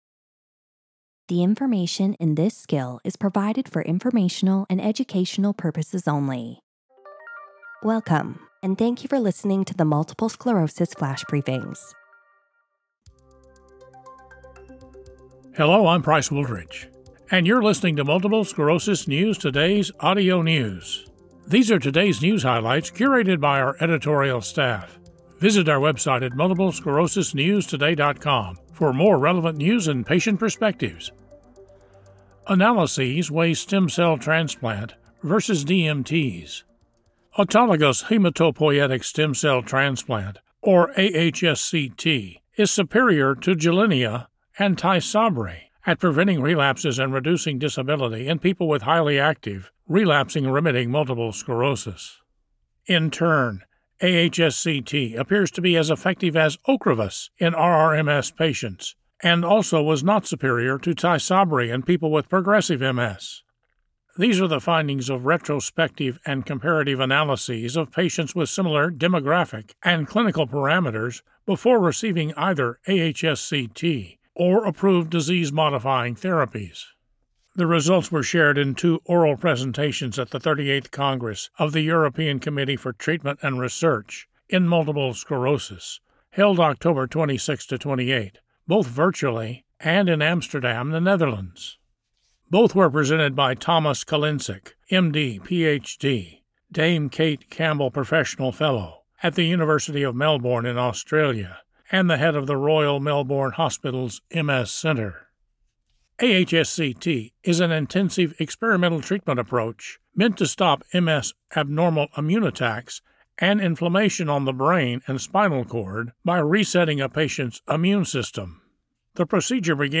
reads a news article on how stem cell transplant is superior to Gilenya and Tysabri at preventing relapses and easing disability in RRMS patients.